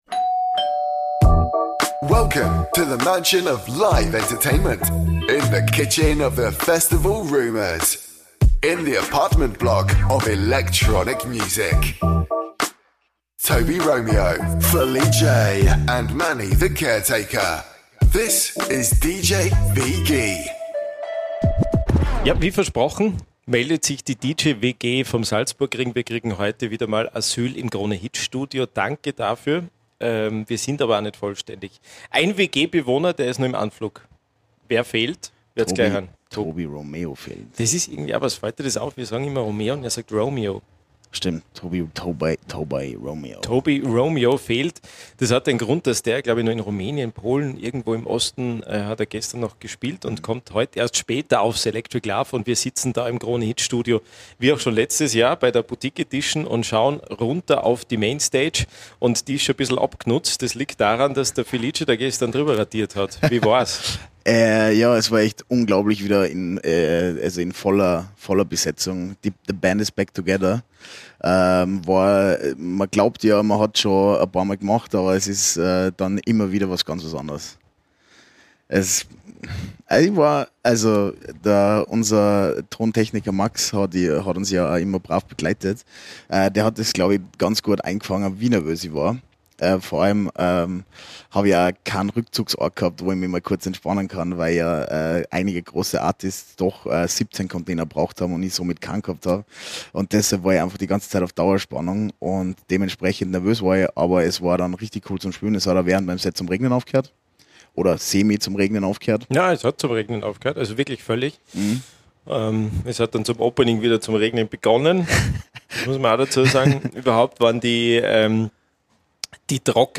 #04 - Live vom ELF 2022 ~ DJ WG Podcast
Direkt vom Electric Love Festival 2022